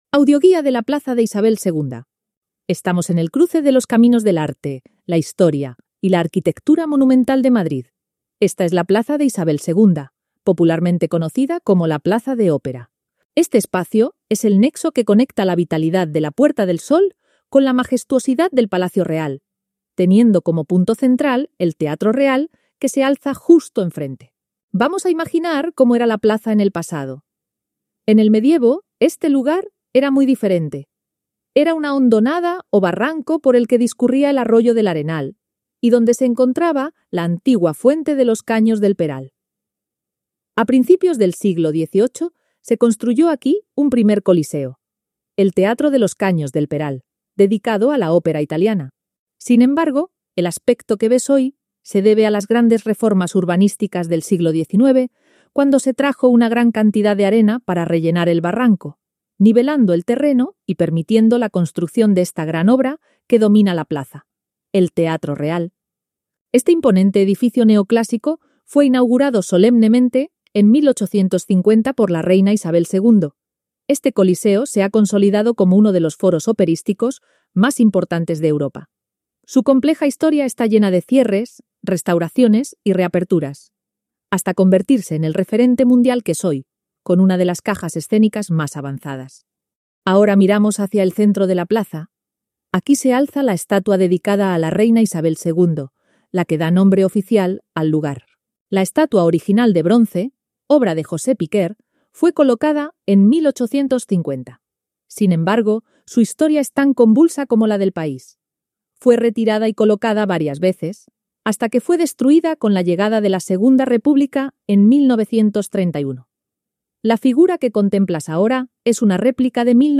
Audioguía: La Plaza de Ópera
AUDIOGUIA-DE-LA-PLAZA-DE-ISABEL-II.mp3